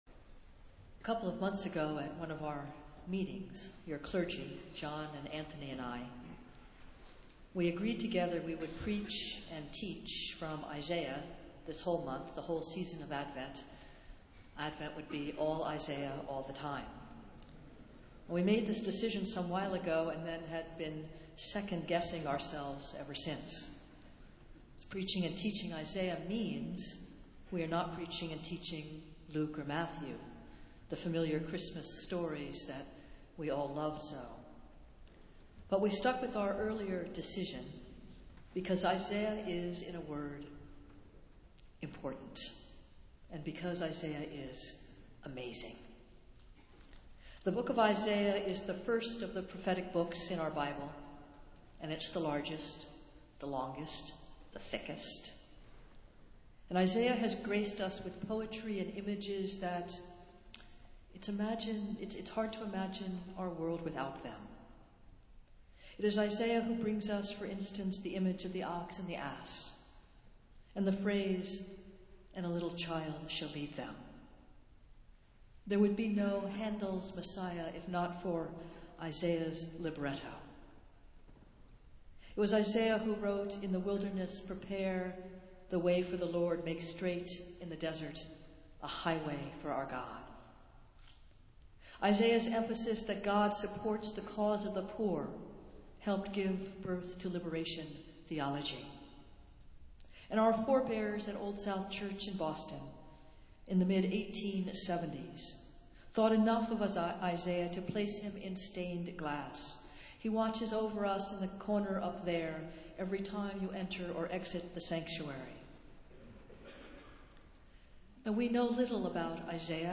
Festival Worship - Second Sunday in Advent